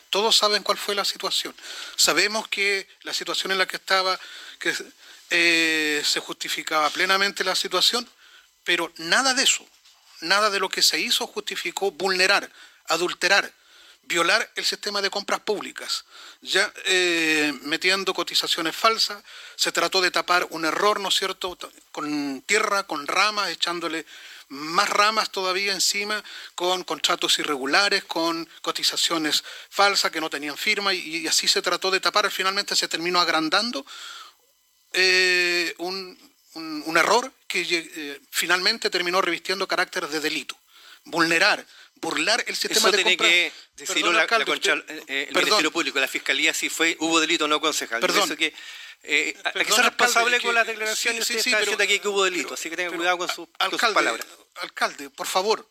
Sesión Concejo Municipal de Futrono | Carputa transmisión municipio